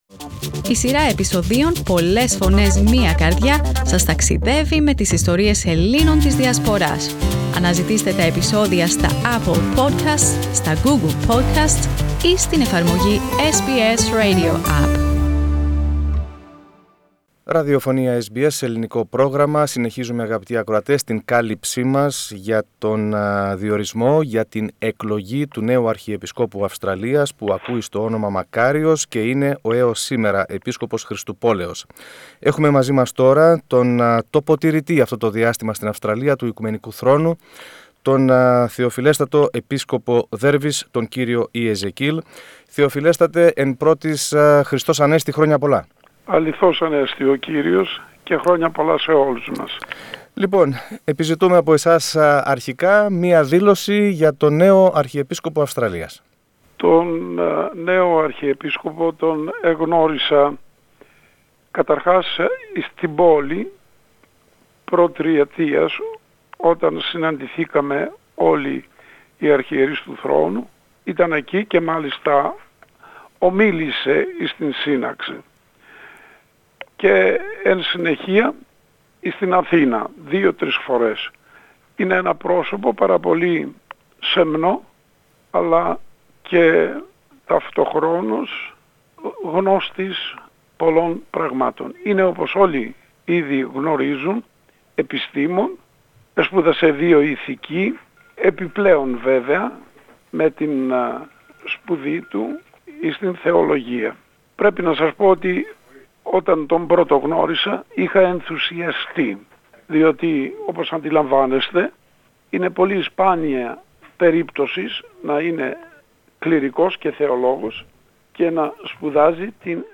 Ως άνθρωπος της σύγχρονης εποχής και σπουδαίο επιστήμονα χαρακτηρίζει τον νέο (εκλελεγμένο) Αρχιεπίσκοπο Αυστραλίας κ. Μακάριο, ο βοηθός Επίσκοπος Δέρβης, κ. Ιεζεκιήλ , ο οποίος αυτή την περίοδο εκτελεί χρέη τοποτηρητή του Οικουμενικού Θρόνου στην Αυστραλία. Στην συνέντευξη που παραχώρησε στο Ελληνικό Πρόγραμμα της Ραδιοφωνίας SBS ο κ. Ιεζεκιήλ, που είναι ο πρεσβύτερος των Επισκόπων που υπηρετούν σήμερα στην Αυστραλία, εκλαμβάνει ως θετική την κατάτμηση της Αρχιεπισκοπής.